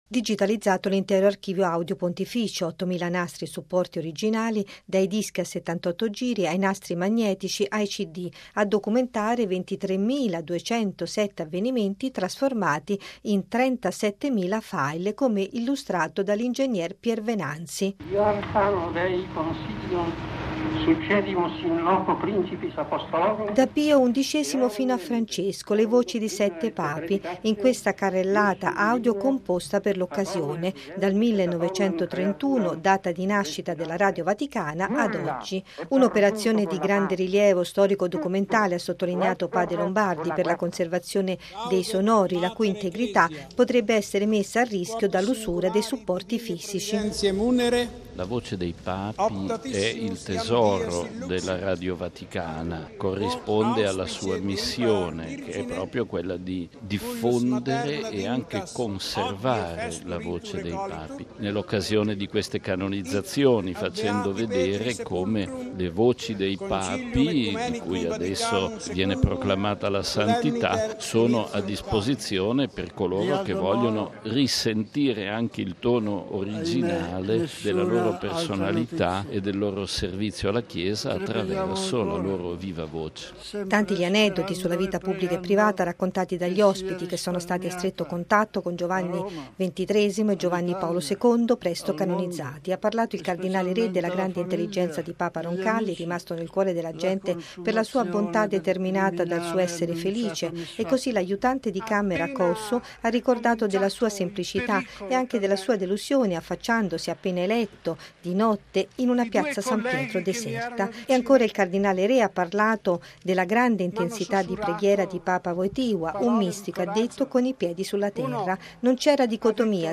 (Parole in latino di Pio XI)
Da Pio XI fino a Francesco, le voci di sette Papi, in questa carrellata audio composta per l’occasione: dal 1931, data di nascita della Radio Vaticana, a oggi.